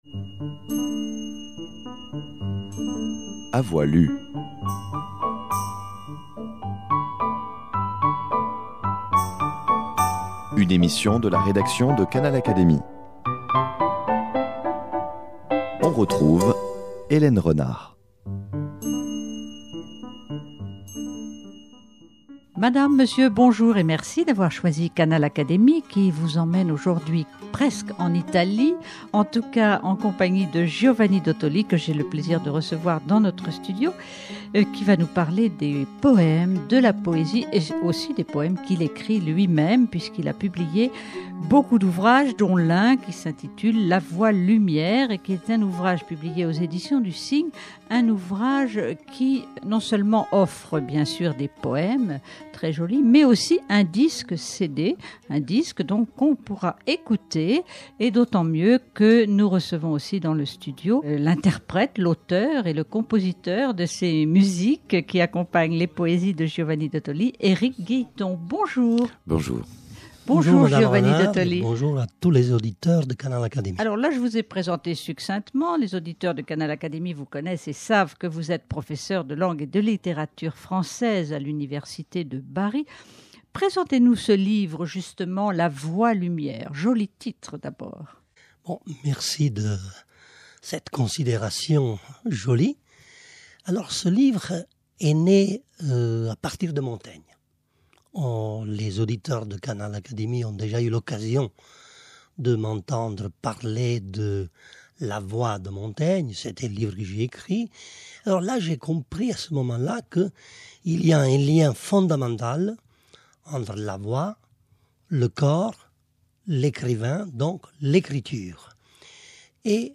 Émission